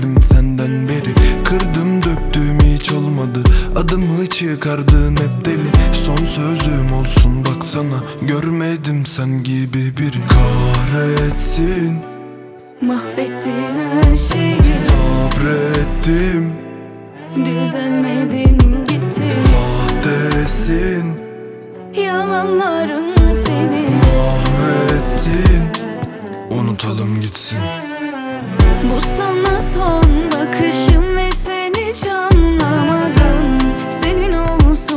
# Arabesque